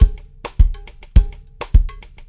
CWS DRUMS+-L.wav